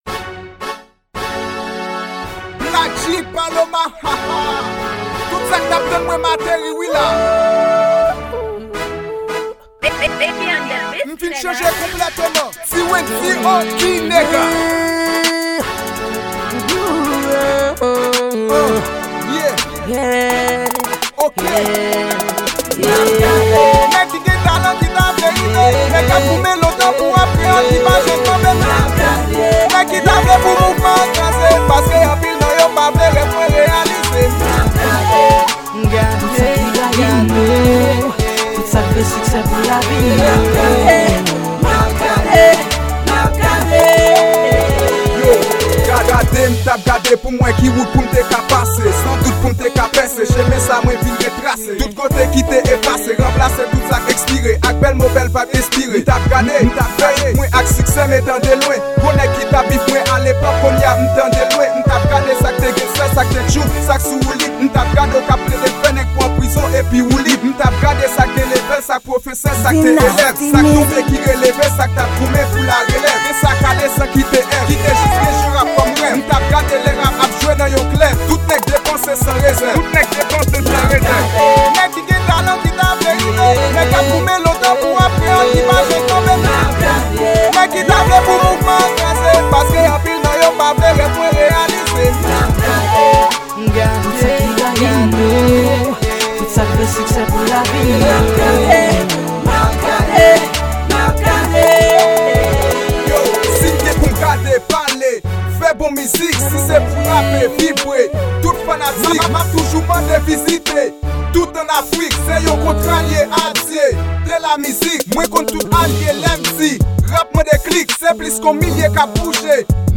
Genre: Rap kreyol.